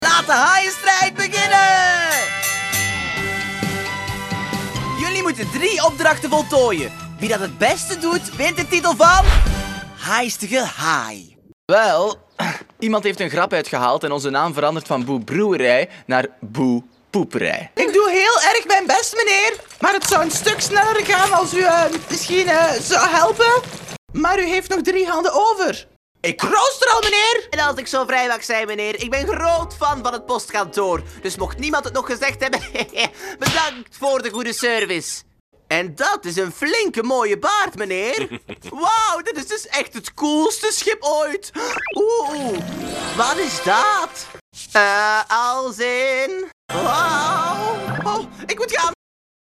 Vlaams
Commercieel, Jong, Stedelijk, Veelzijdig, Vriendelijk